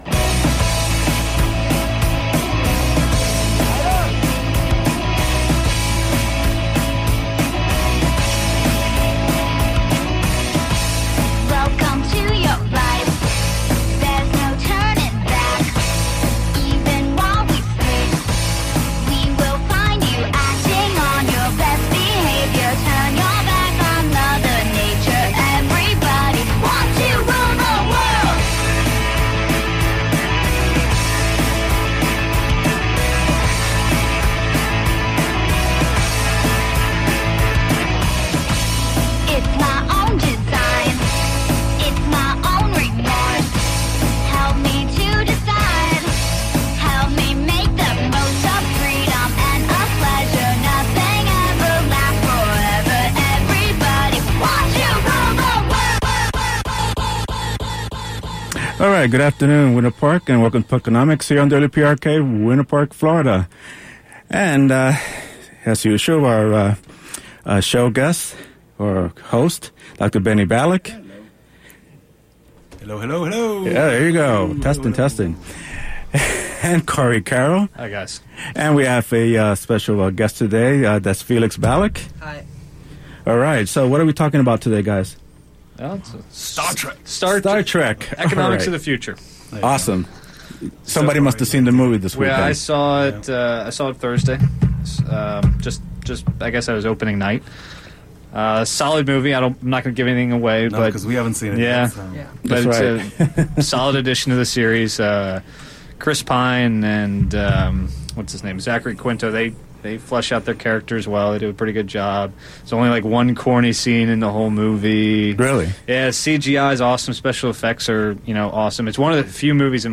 We also had a great caller